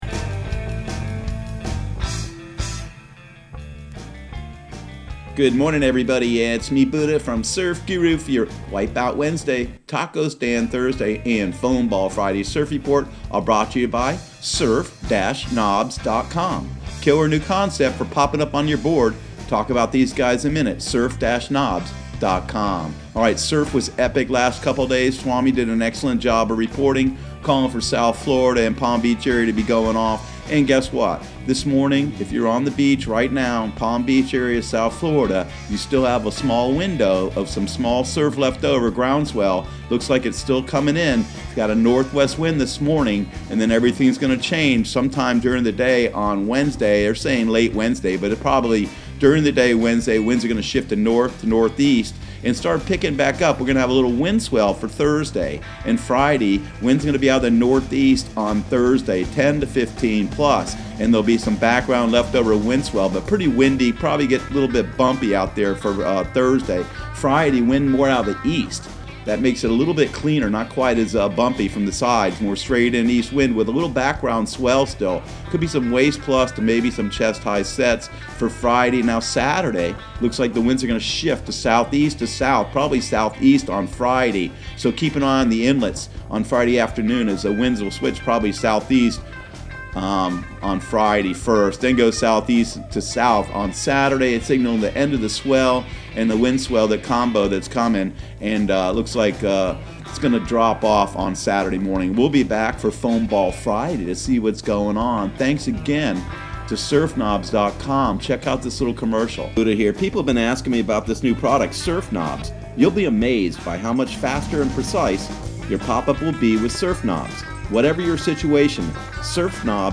Surf Guru Surf Report and Forecast 11/20/2019 Audio surf report and surf forecast on November 20 for Central Florida and the Southeast.